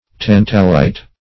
Tantalite \Tan"ta*lite\, n. [Cf. F. tantalite.] (Min.)